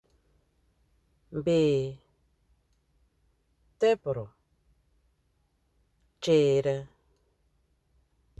Accueil > Prononciation > ee > ee